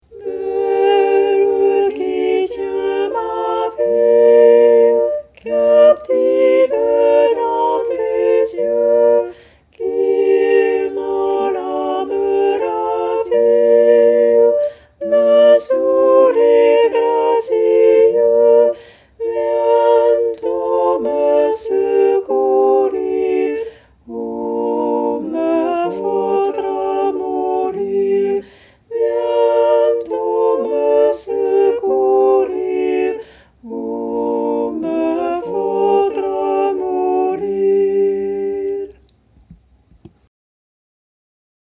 VeRSION CHANTEE
Pavane-Sopranes.mp3